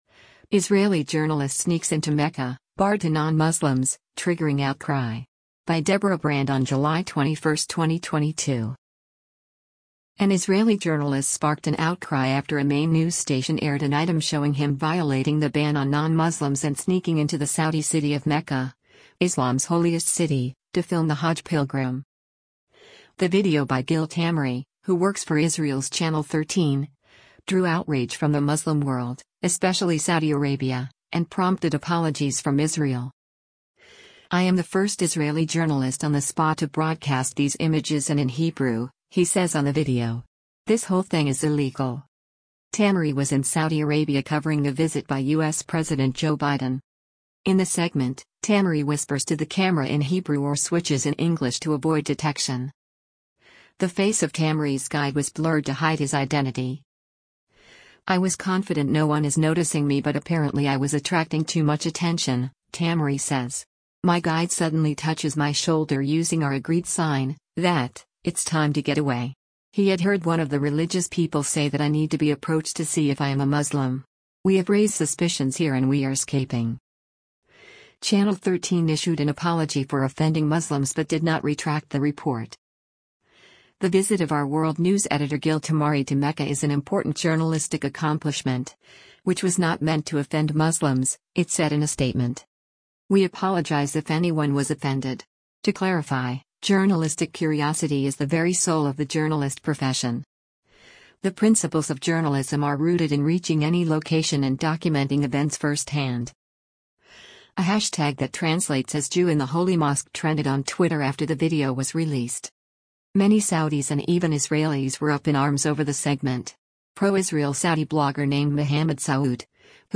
whispers to the camera in Hebrew or switches in English to avoid detection